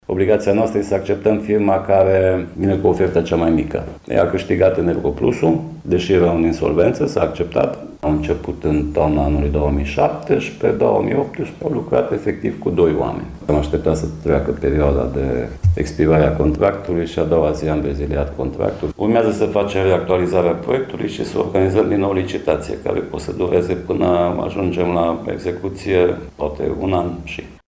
Primarul comunei, Laurențiu Boar: